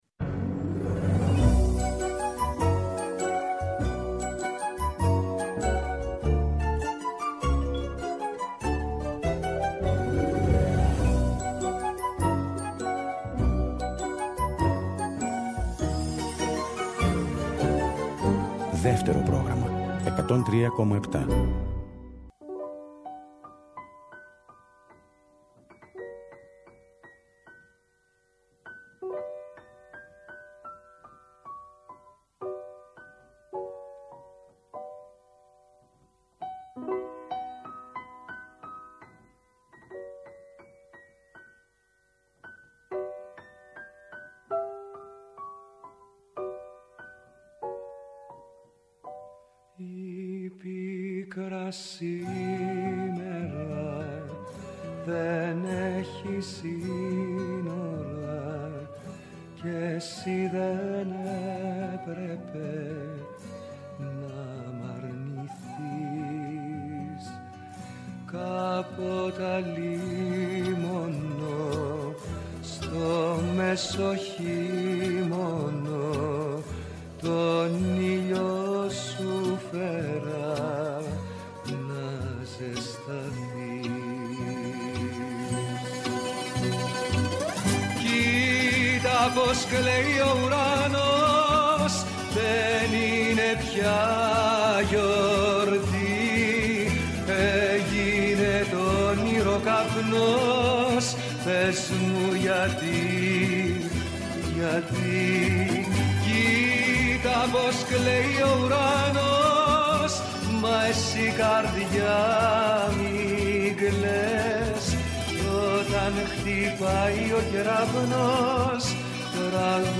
Στο “Μελωδικό Αντίδοτο” oι καινούριες μουσικές κάνουν παρέα με τις παλιές αγαπημένες σε μια ώρα ξεκούρασης καθώς επιστρέφουμε από μια κουραστική μέρα.